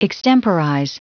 Prononciation du mot extemporize en anglais (fichier audio)
Prononciation du mot : extemporize